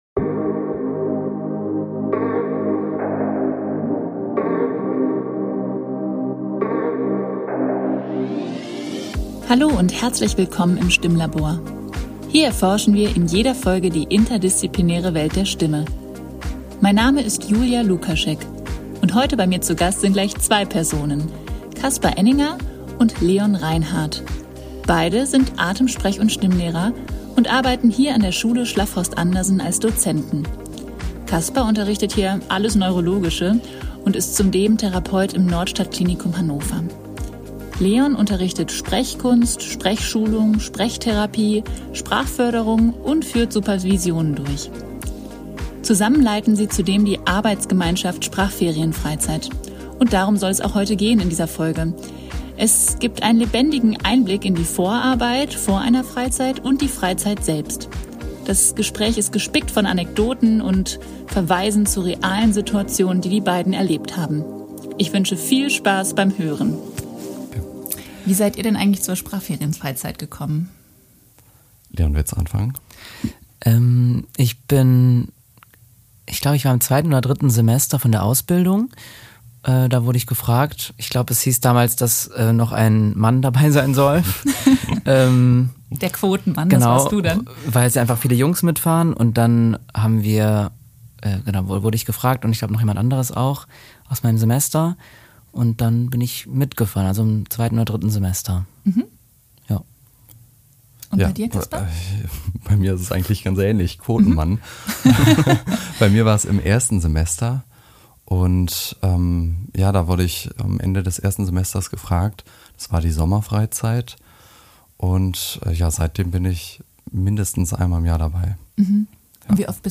Es gibt einen lebendigen Einblick in die Vorabreit vor einer Freizeit und die Freizeiten selbst. Das Gespräch ist gespickt von Anekdoten und Verweisen zu realen Situationen.